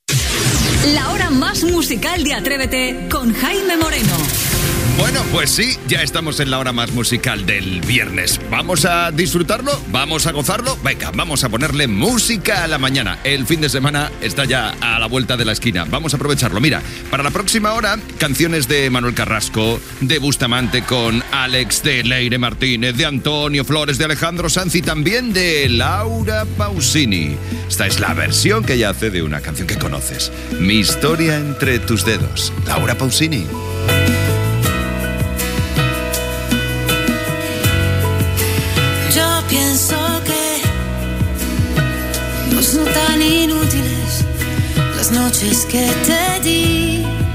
Musical
Presentador/a
FM